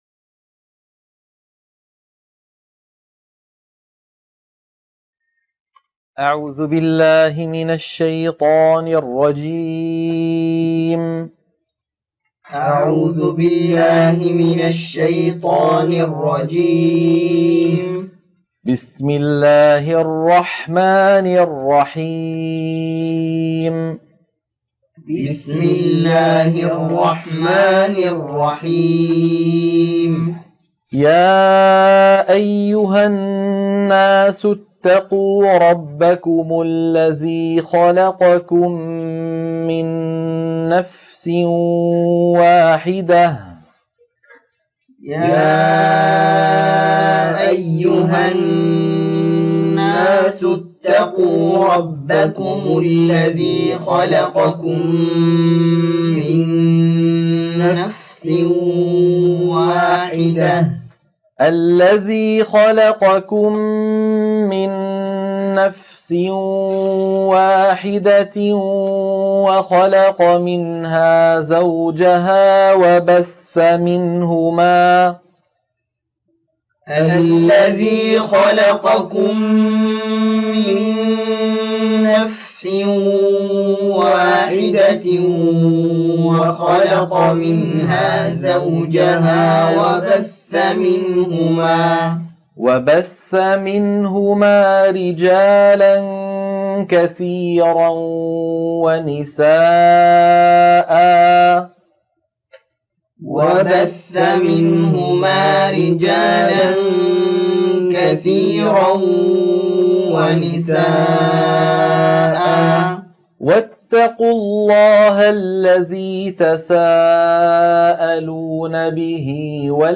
عنوان المادة تلقين سورة النساء - الصفحة 77 التلاوة المنهجية